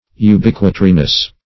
Search Result for " ubiquitariness" : The Collaborative International Dictionary of English v.0.48: Ubiquitariness \U*biq"ui*ta*ri*ness\ ([-u]*b[i^]k"w[i^]*t[asl]*r[i^]*n[e^]s), n. Quality or state of being ubiquitary, or ubiquitous.
ubiquitariness.mp3